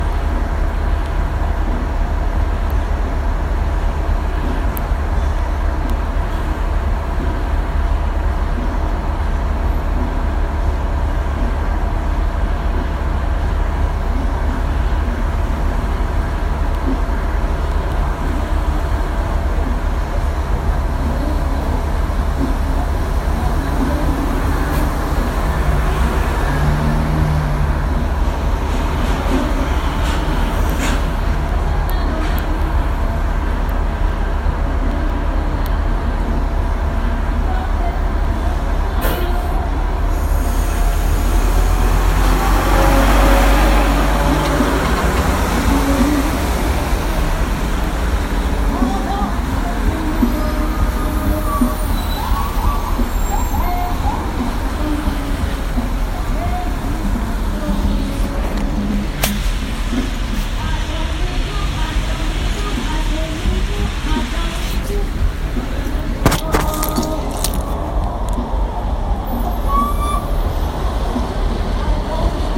[The recording here is of a soundscape at 7th street and Florida Avenue NW, a busy intersection at the north edge of Shaw.  Recorded on a mid-September afternoon, you can hear go-go music (DC’s indigenous subgenre of funk), engines idling, and the whoop of a siren.
shaw-soundwalk-clip.mp3